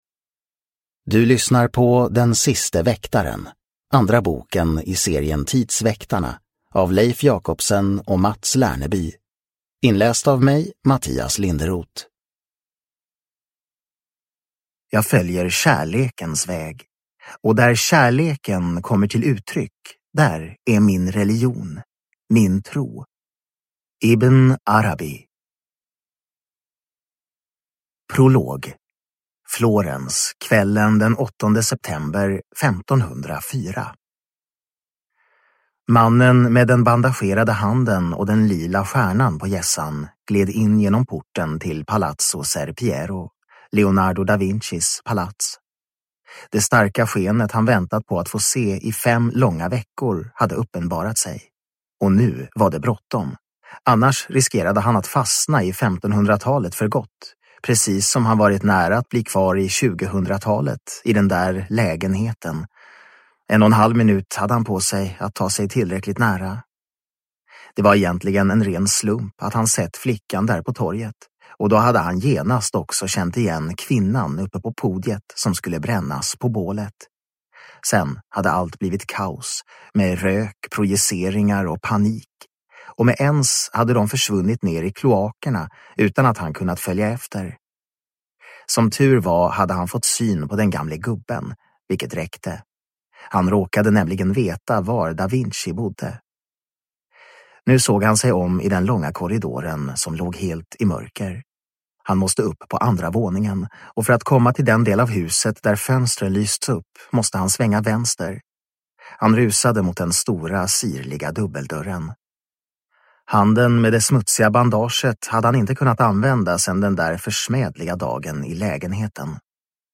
Den siste väktaren (ljudbok) av Mats Lerneby